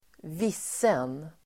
Uttal: [²v'is:en]